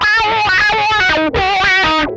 MANIC WAH 13.wav